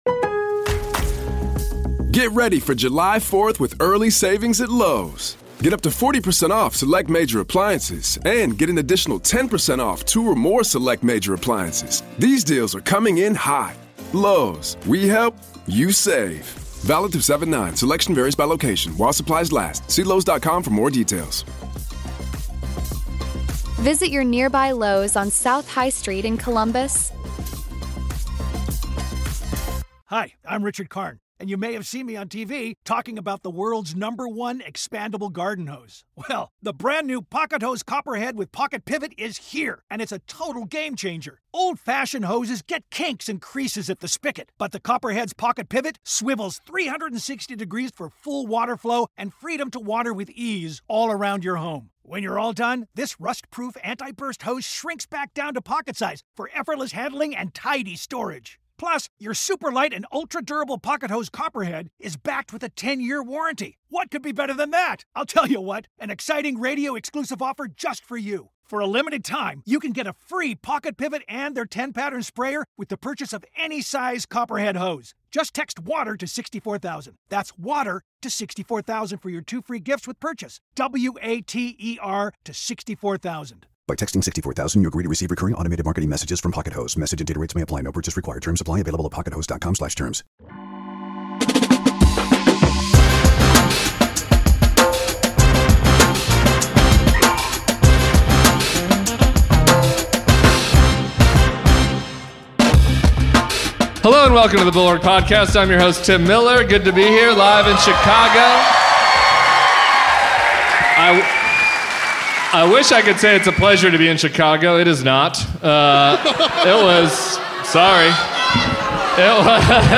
Live from Chicago
Adam Kinzinger joined Tim on stage in the Windy City Wednesday night to dis Chicken Trump, who's made bitcoin bros, private prisons, his family and friends—and himself—the main winners in his 'TACO' trade economy.